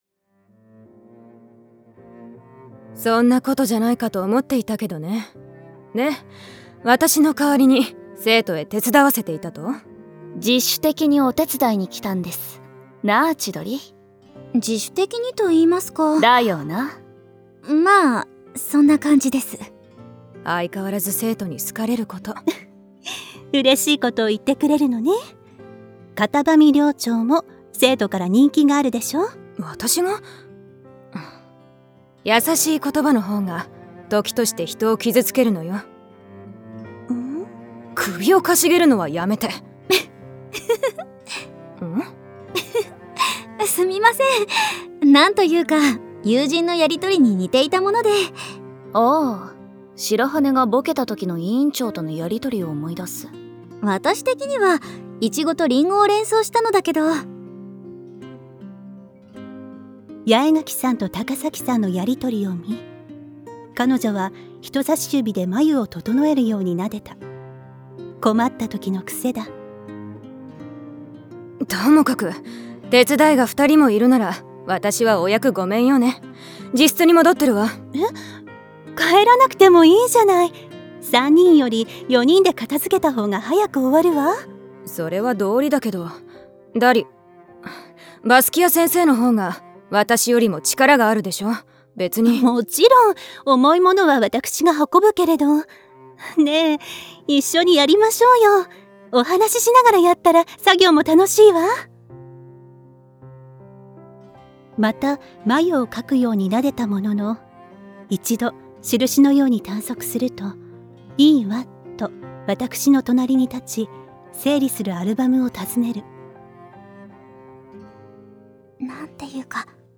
スノウホワイト　サンプルボイス
本作はその方喰たまきとダリア＝バスキアの友愛を描いたサウンドドラマCDです。
flowers_sw_samplevoice.mp3